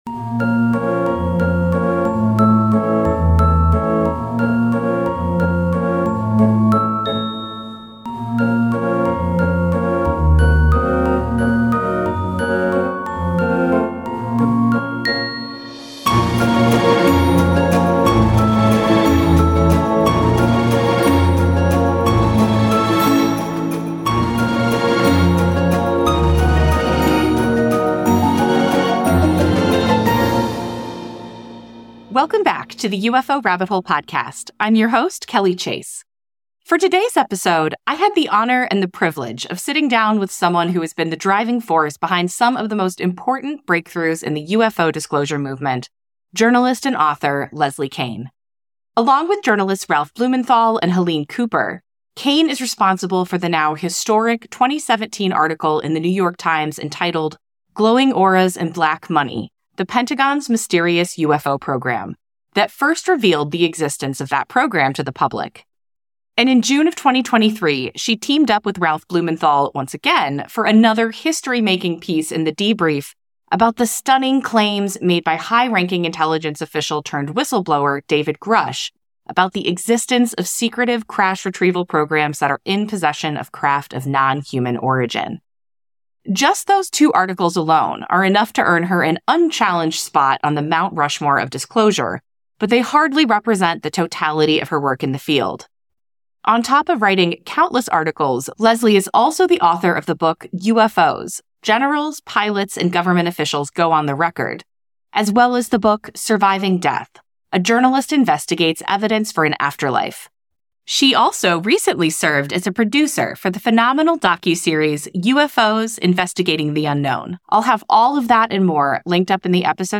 [The UFO Rabbit Hole] Ep 30: An Interview with Leslie Kean: On Disclosure and Surviving Death